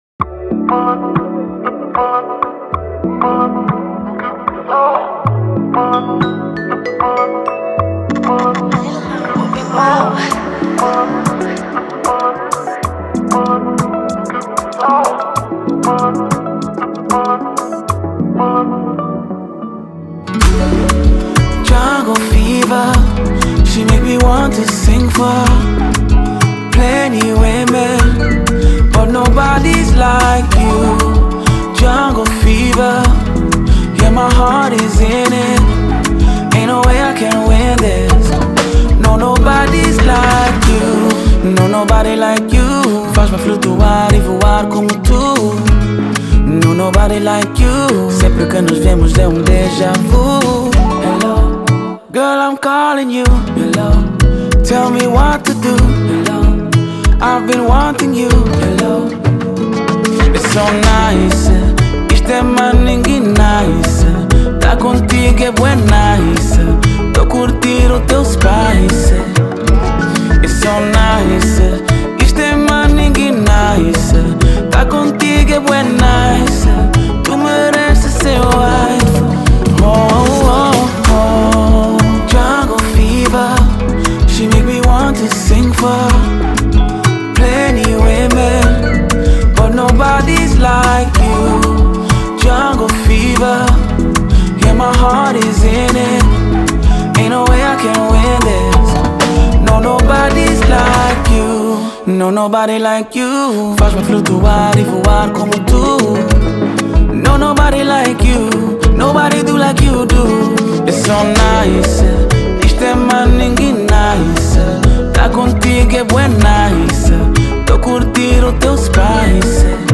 Kizomba Ano de Lançamento